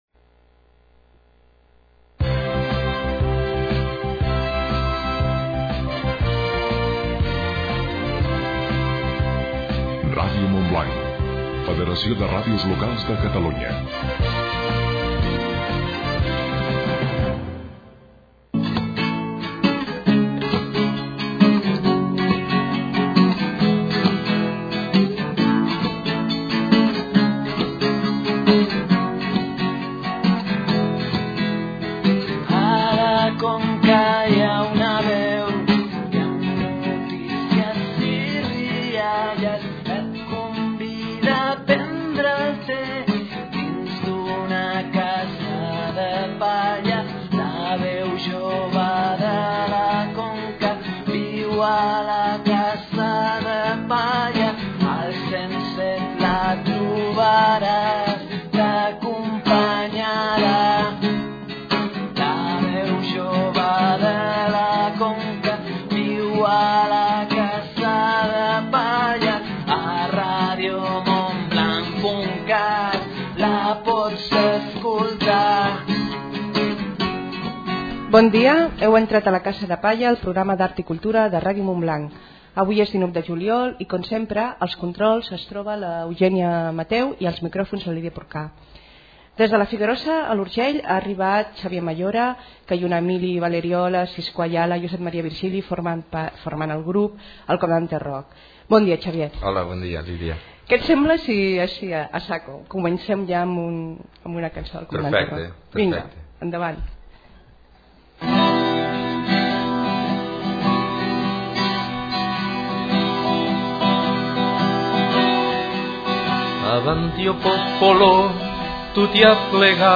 i escoltem la seva música en directe.